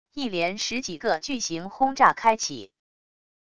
一连十几个巨型轰炸开启wav下载